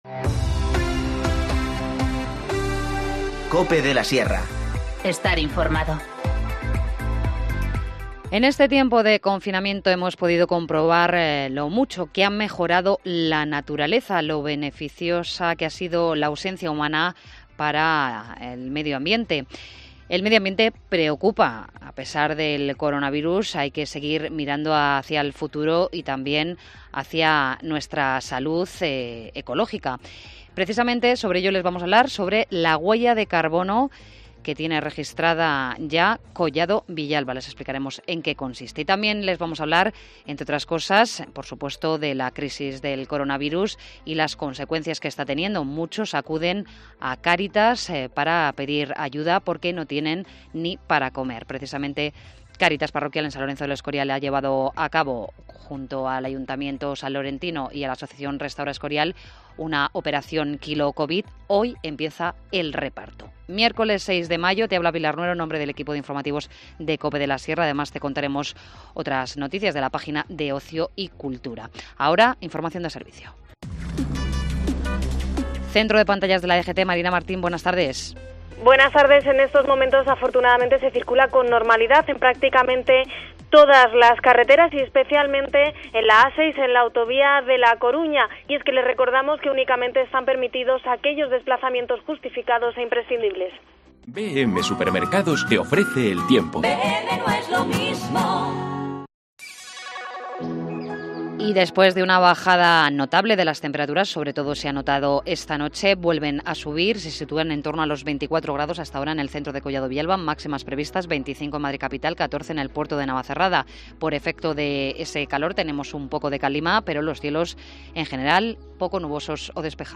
Informativo Mediodía 6 mayo 14:20h